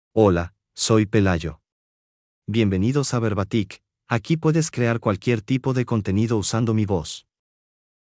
MaleSpanish (Mexico)
Pelayo — Male Spanish AI voice
Voice sample
Listen to Pelayo's male Spanish voice.
Pelayo delivers clear pronunciation with authentic Mexico Spanish intonation, making your content sound professionally produced.